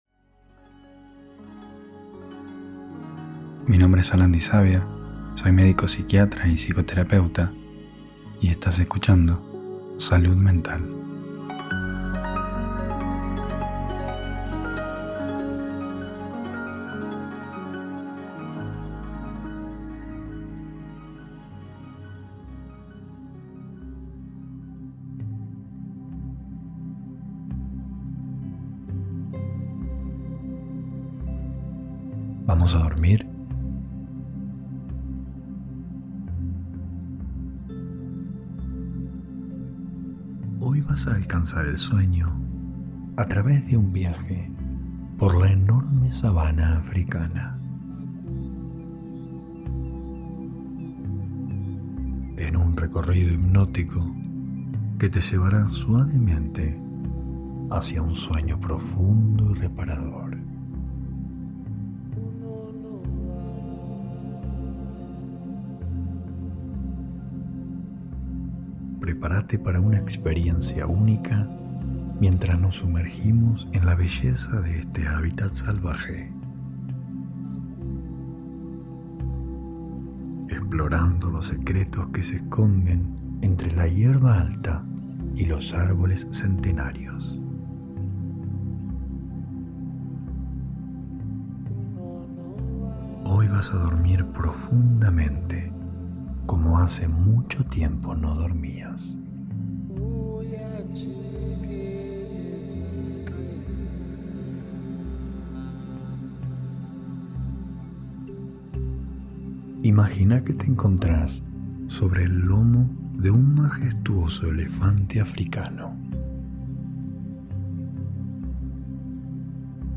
Hipnosis para dormir - Mayo 2024 ✨
Hipnosis guiada para dormir.